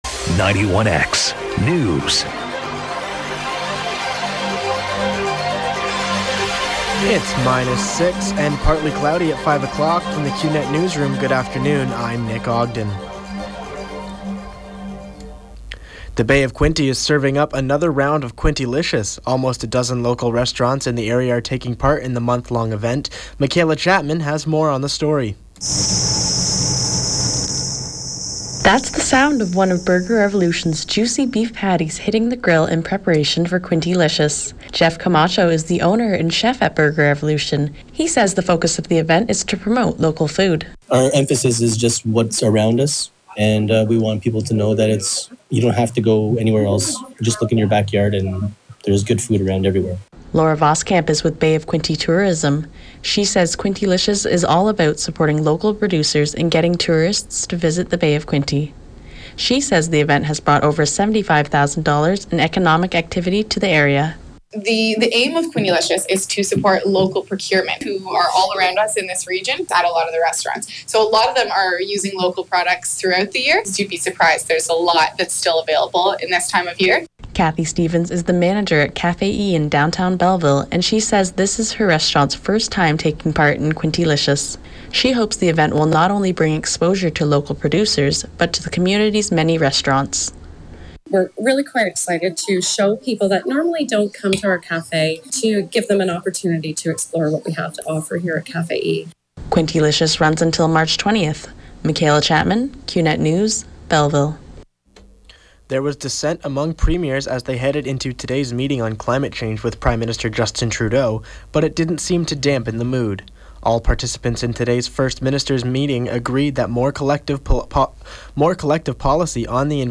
91X Newscast – Thursday, March 3, 2016, 5 p.m.